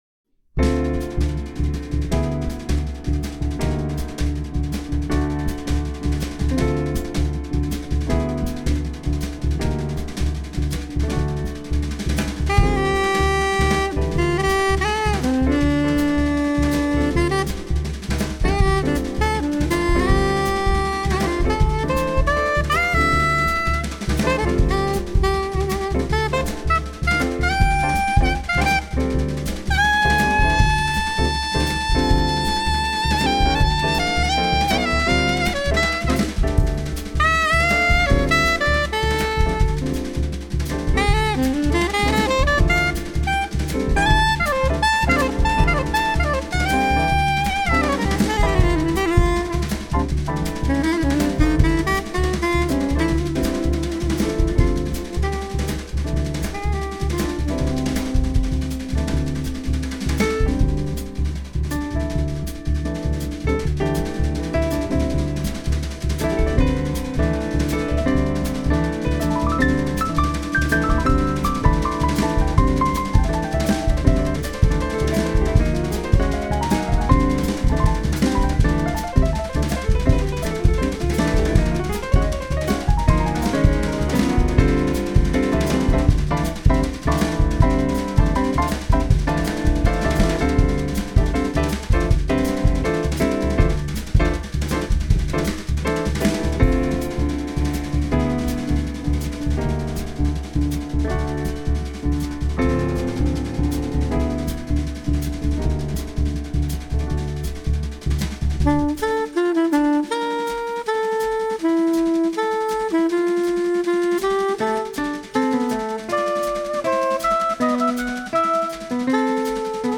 all saxes
piano, electric piano
double bass, banjo
drums